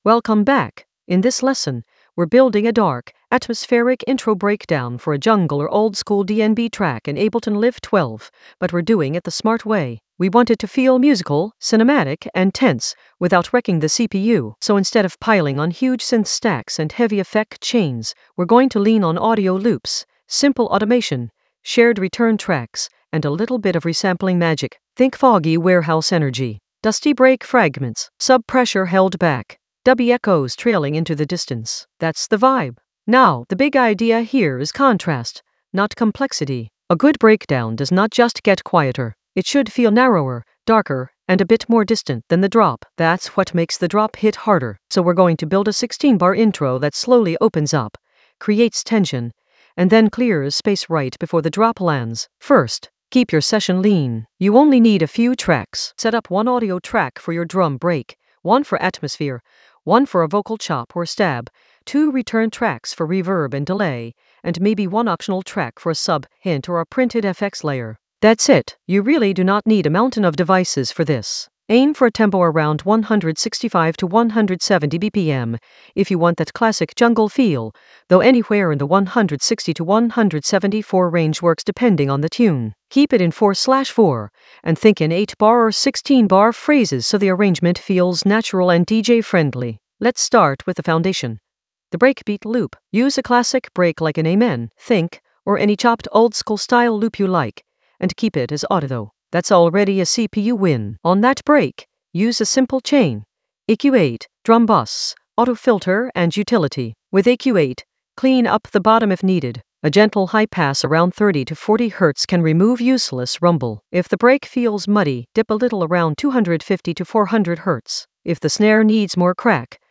An AI-generated intermediate Ableton lesson focused on Breakdown for intro with minimal CPU load in Ableton Live 12 for jungle oldskool DnB vibes in the FX area of drum and bass production.
Narrated lesson audio
The voice track includes the tutorial plus extra teacher commentary.